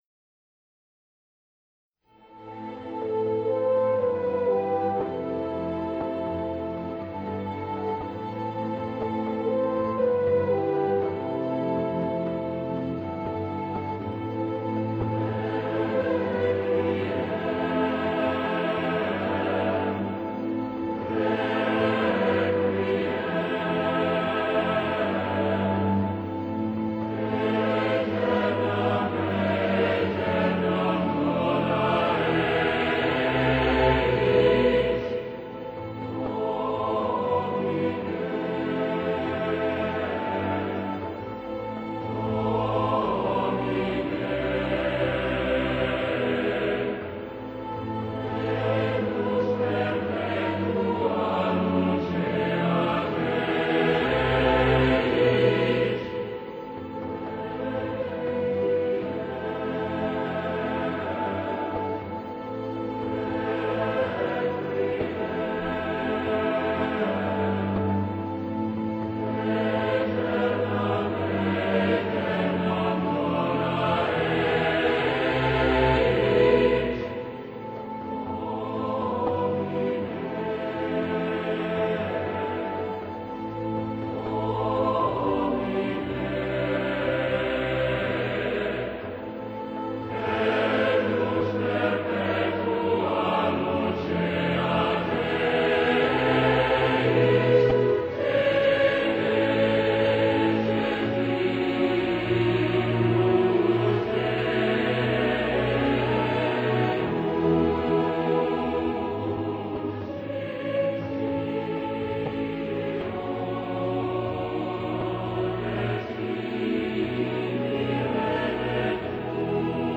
全编制的交响乐团和大型合唱团共同营造了宏大的音场，各个声部对位清晰、层次分明